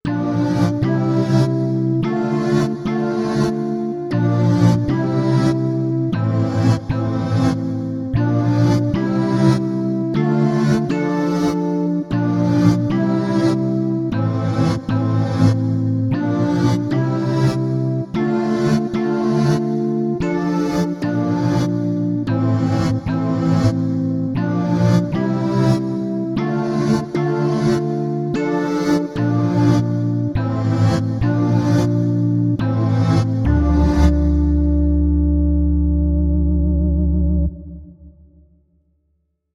Lead III
waldorf_quantum_test__lead_3.mp3